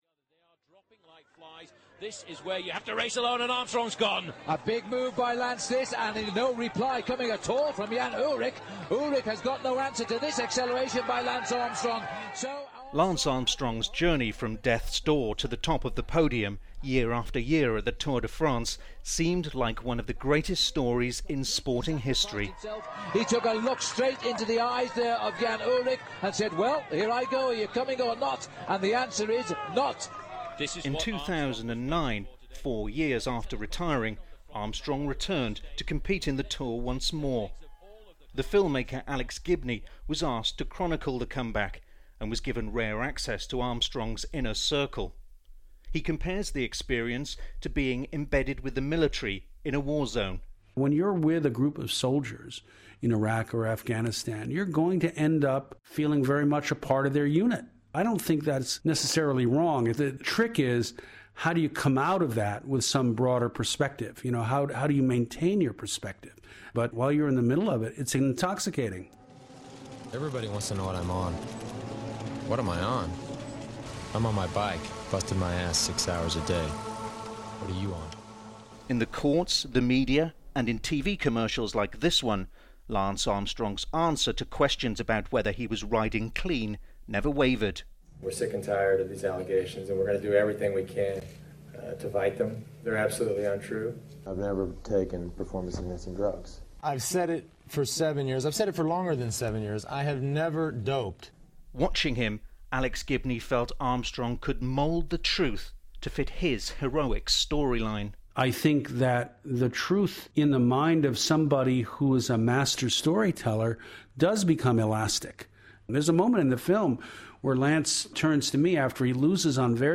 TX: Radio 4 PM programme. 18th October 2013.